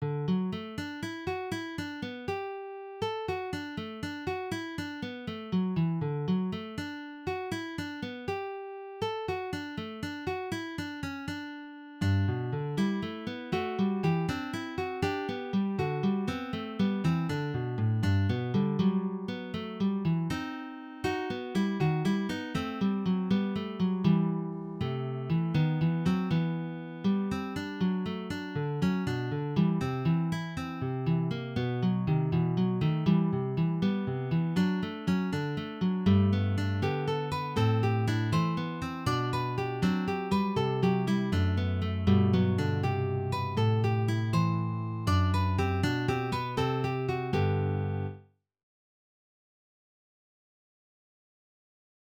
Fugue in progress